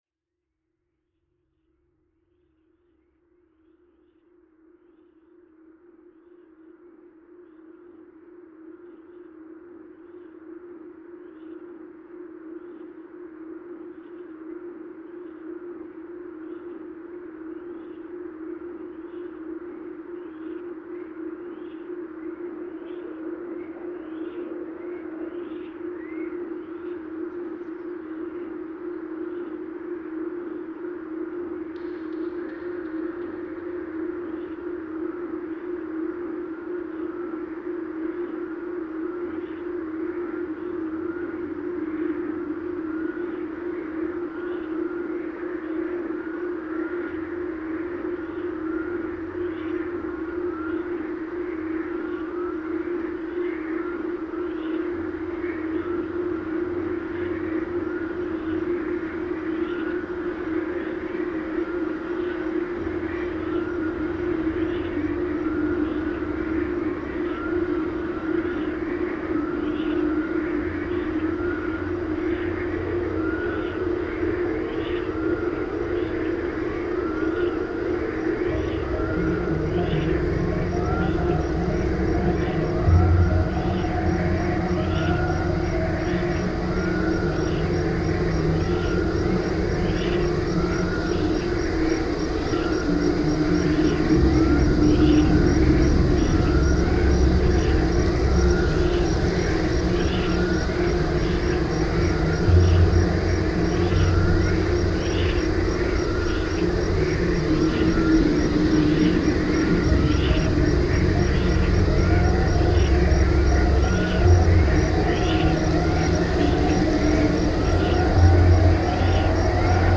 Ningaloo wind turbine